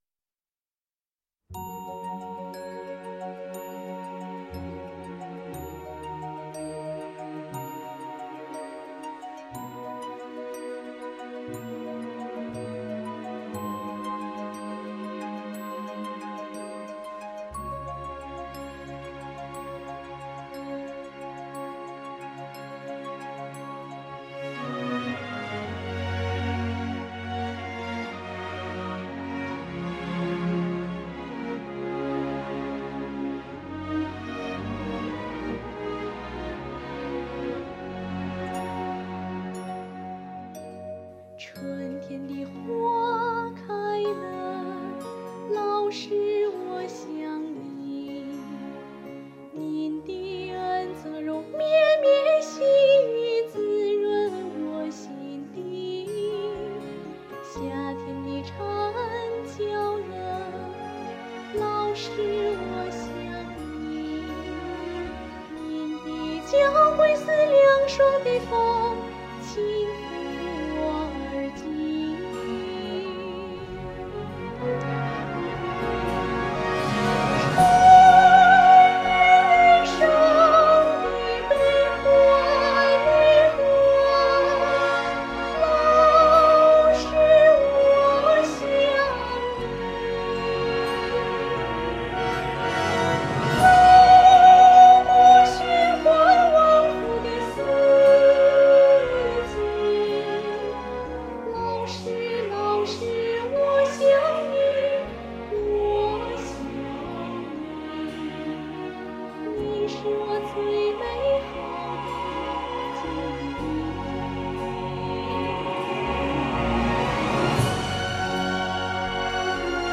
唱的有些太压制了。声音很好，背景音乐没有必要放这么大声，要凸显你的声音才能表达感觉。气稍微有些短。